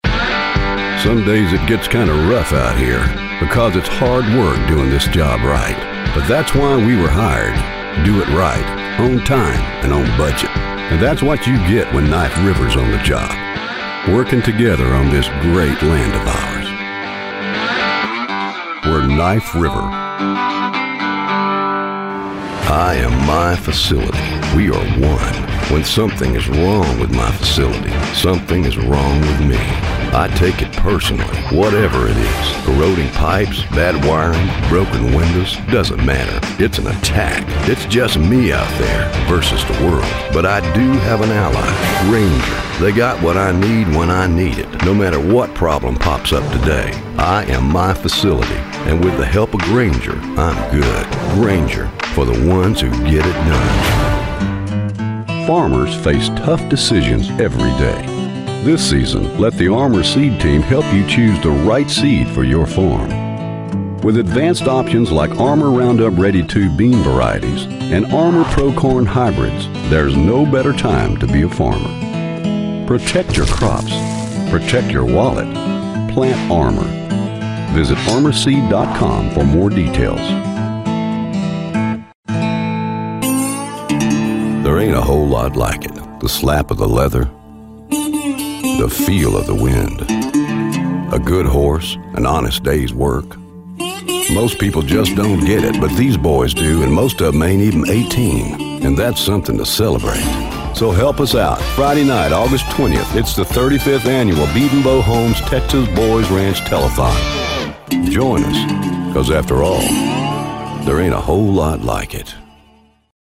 Blue Collar Demo
Slight southern, Texas accent
Middle Aged